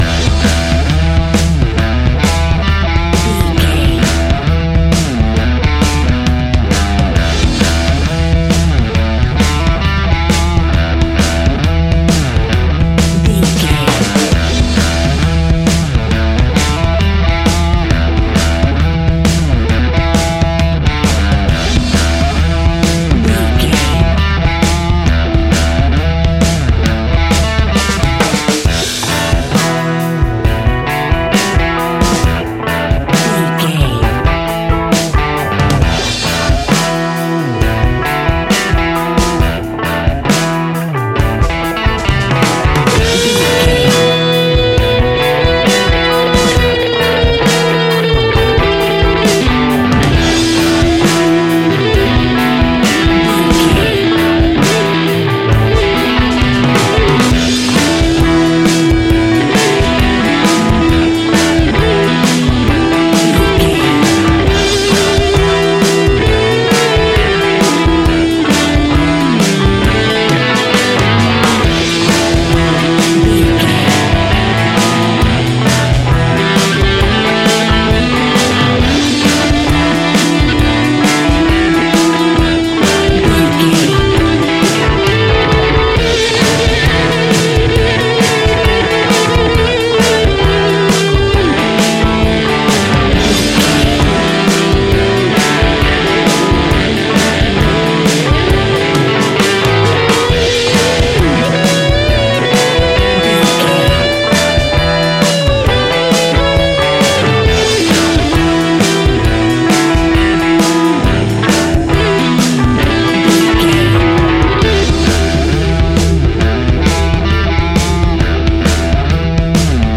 Aeolian/Minor
angry
heavy
aggressive
electric guitar
drums
bass guitar